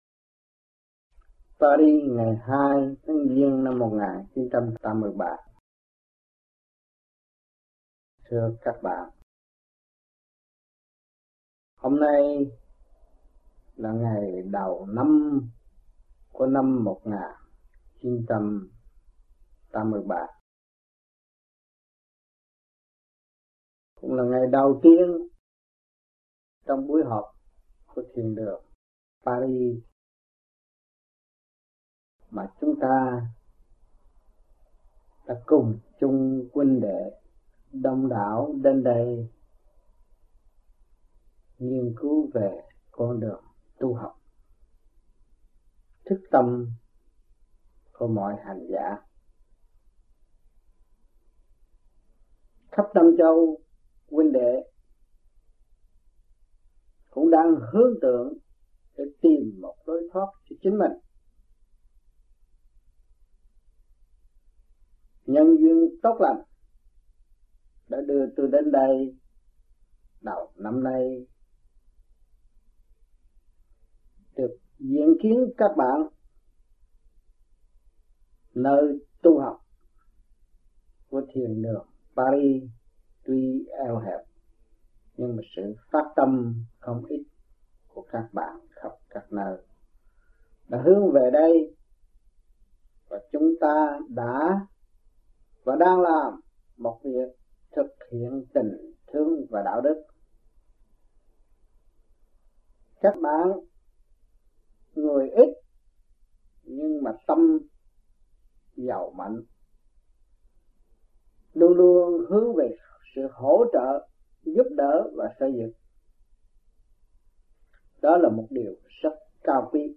1983-01-02 - Paris - Đời Đạo Giảng Tại Thiền Đường Paris